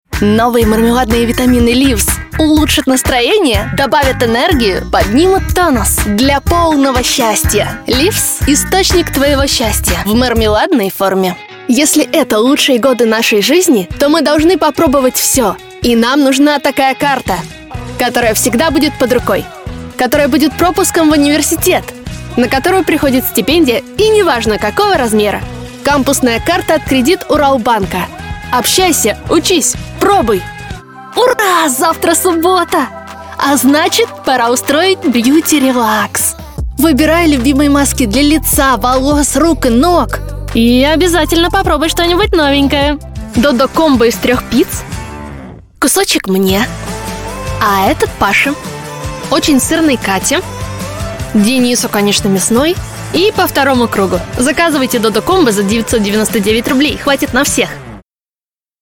Нежный, жизнерадостный, романтичный, высокий и молодой голос. Особенно специализируюсь на нативной, естественной подаче.
Тракт: Микрофон: Neumann TLM-103 Обработка: Long VoiceMaster Звуковая карта: SSL 2+ Акустическая кабина